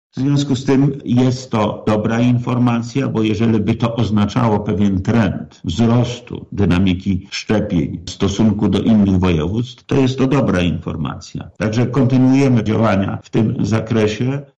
Dziś to tylko 1059 – tłumaczy Sprawka: